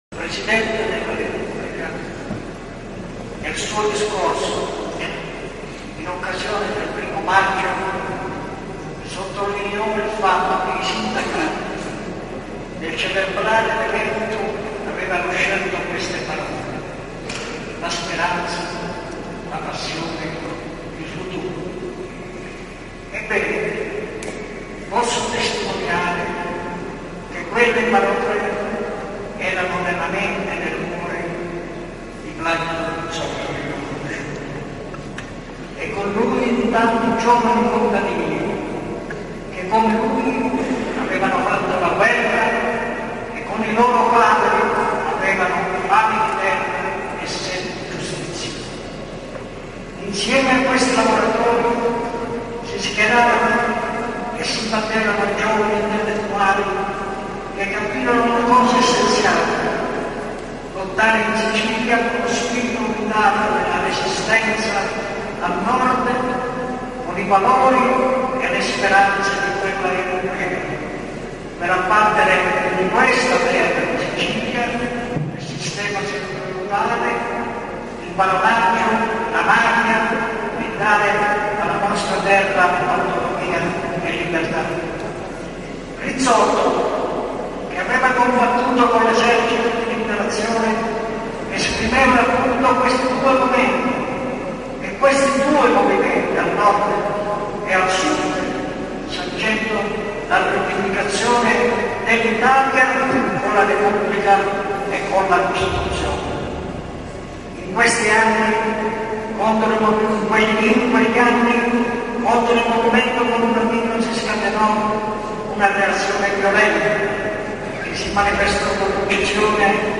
Guarda il video sen. Emanuele Macaluso 4' 56'' 482 Scarica mp3